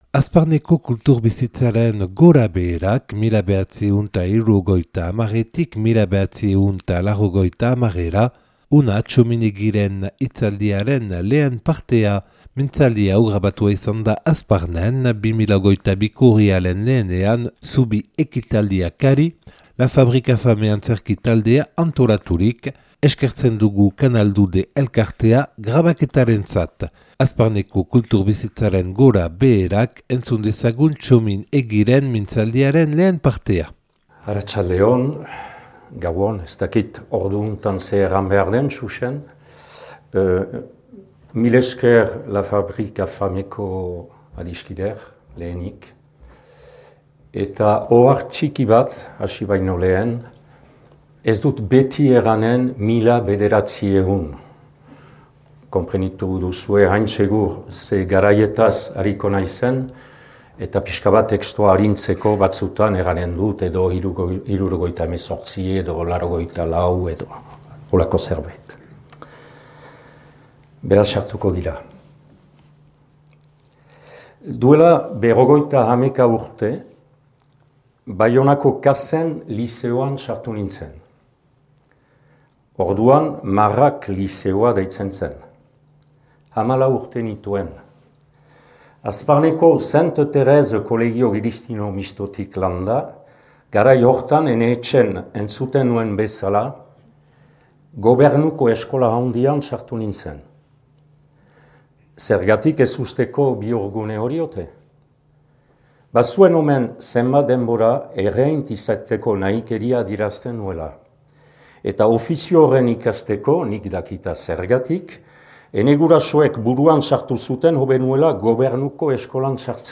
Urriaren 1a Zubi ekitaldiakari.